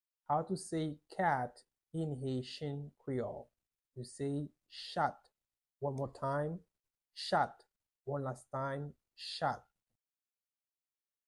Listen to and watch “chat” audio pronunciation in Haitian Creole by a native Haitian  in the video below:
6.How-to-say-Cat-in-Haitian-Creole-–-chat-with-Pronunciation.mp3